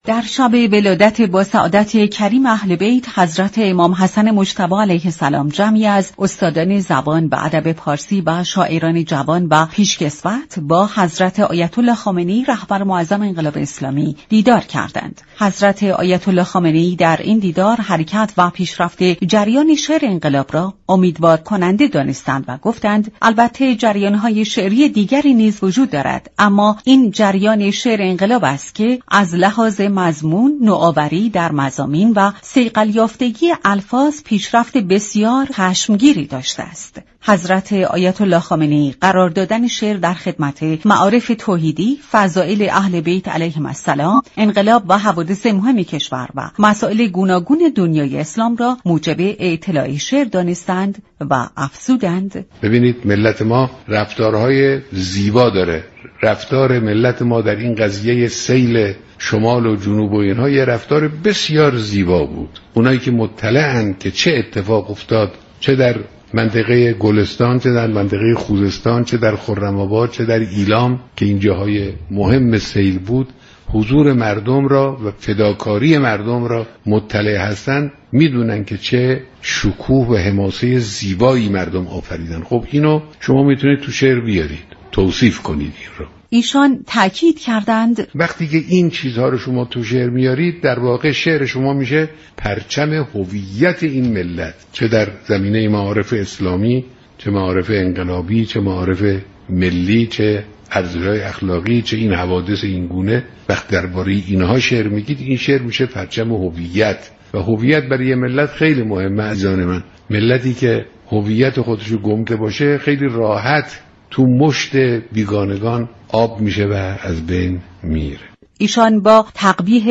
دریافت فایل دكتر سید محمد حسینی وزیر اسبق فرهنگ و ارشاد اسلامی در گفت و گو با برنامه جهان سیاست درباره دیدار جمعی از شاعران با مقام معظم رهبری و تاكید ایشان بر ضرورت توجه به زبان فارسی، گفت: رهبر معظم انقلاب اسلامی همواره در بیاناتشان بر ترویج زبان معیار، صحیح و صیقل خورده زبان پارسی تاكید دارند و با نام بردن از شاعران كهن و به نام ایران توجه همگان را به سوی این امر مهم جلب می كنند.